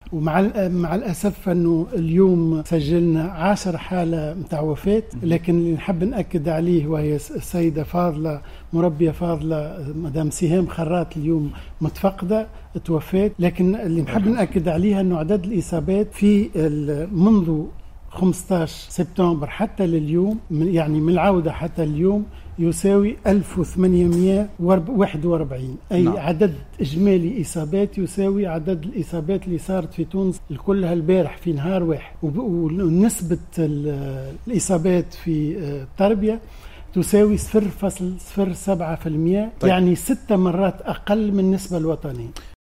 قال وزير التربية فتحي السلاوتي في تصريح لقناة "الوطنية" أنه تم اليوم السبت تسجيل عاشر حالة وفاة لمربّية وهي متفقّدة تربية.